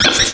Cri de Chlorobule dans Pokémon Noir et Blanc.